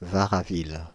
French pronunciation of « Varaville »